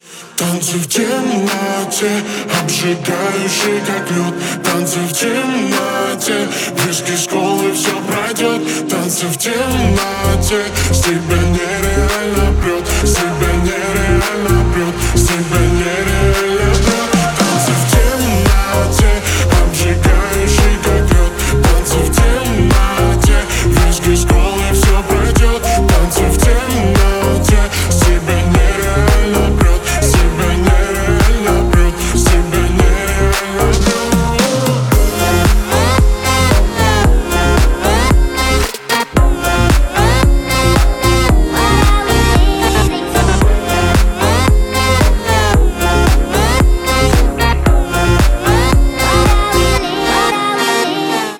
• Качество: 128, Stereo
ритмичные
заводные